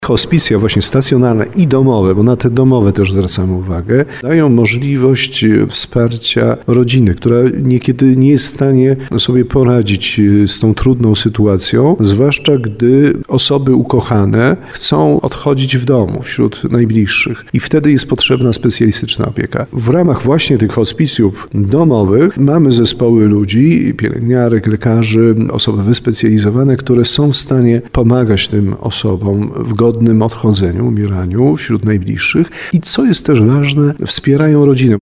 mówi biskup tarnowski Andrzej Jeż.